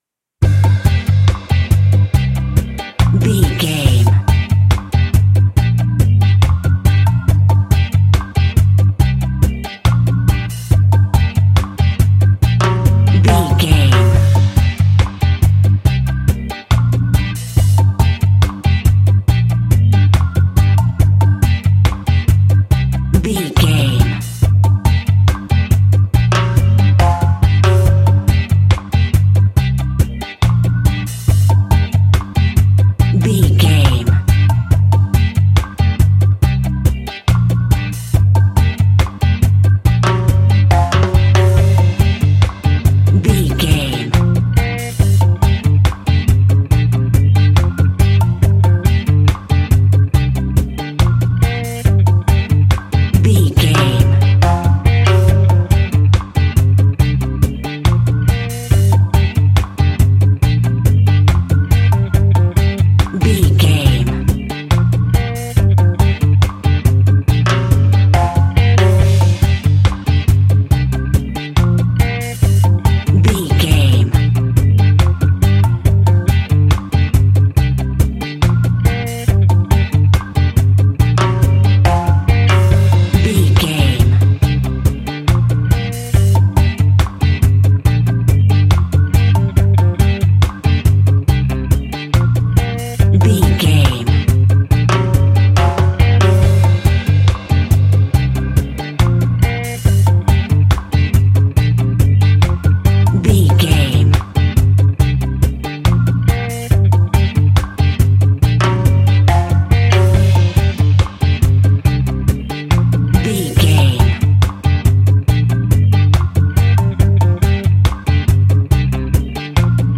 Classic reggae music with that skank bounce reggae feeling.
Aeolian/Minor
dub
reggae instrumentals
laid back
chilled
off beat
drums
skank guitar
hammond organ
percussion
horns